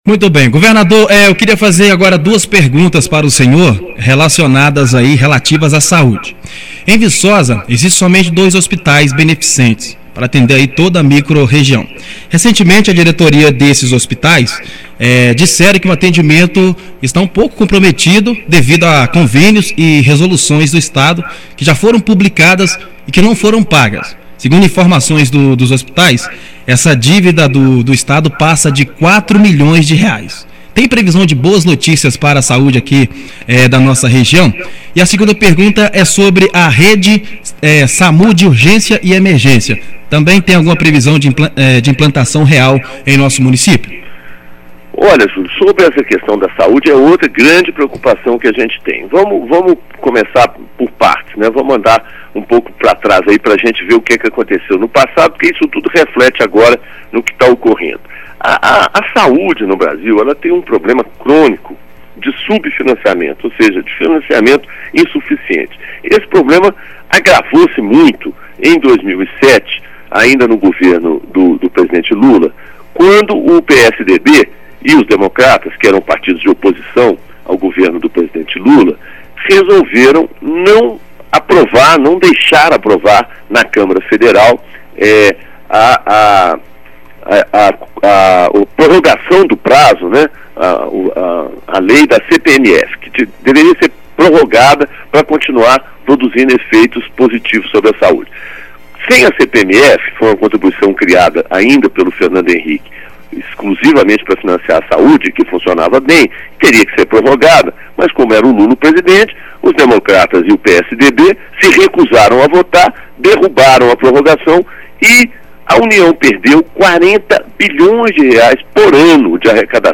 GOVERNADOR DE MG FERNANDO PIMENTEL CONCEDE ENTREVISTA EXCLUSIVA À RÁDIO MONTANHESA